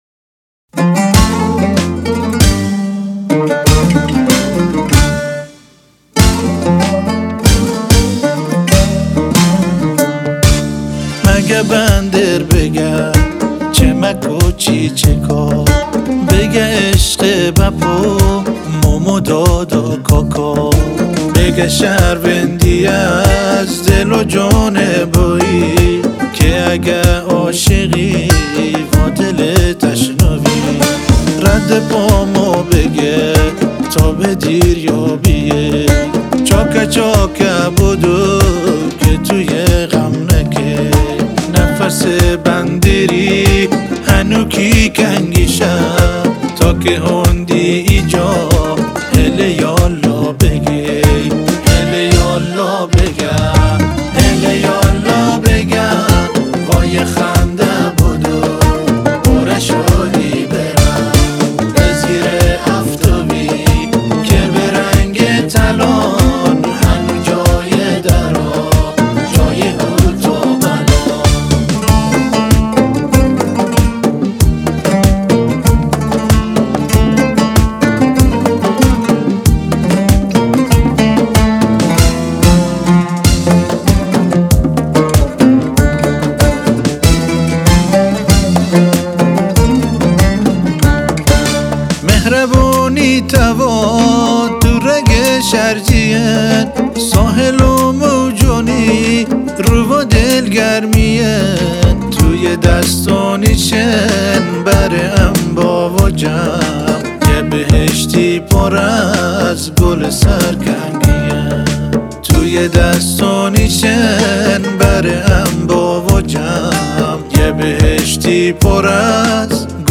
🔸عود
🔸دهل و کسر